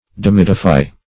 Demideify \Dem"i*de"i*fy\v. t. To deify in part.